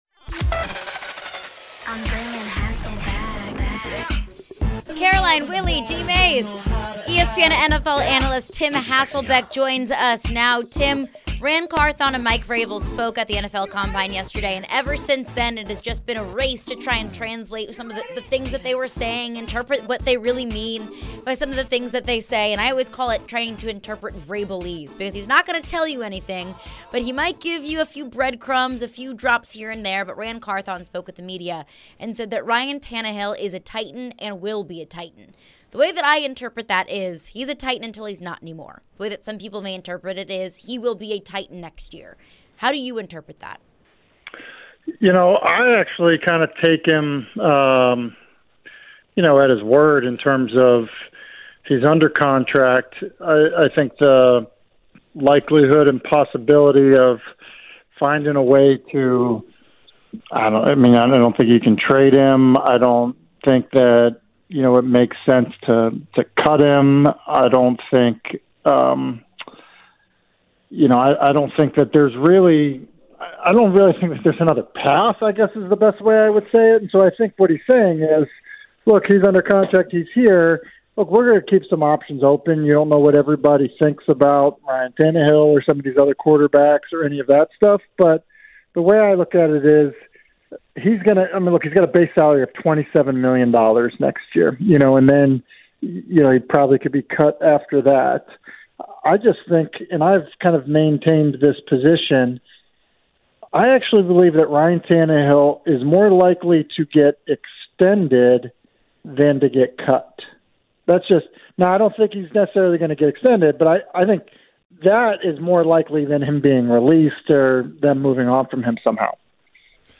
Tim Hasselbeck Interview (3-1-23)